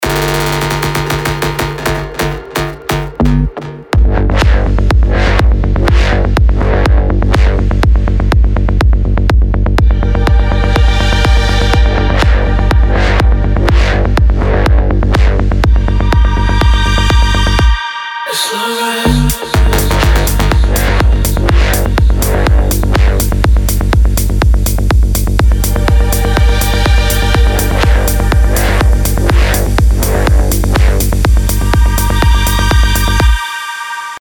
• Качество: 320, Stereo
транс
Жанр: Trance